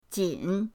jin3.mp3